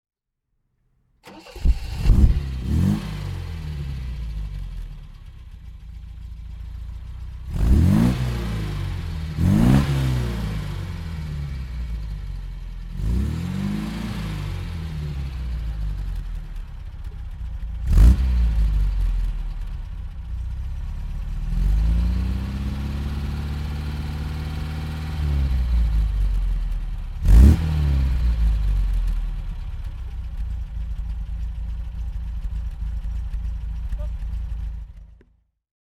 MG B (1972) - Starten und Leerlauf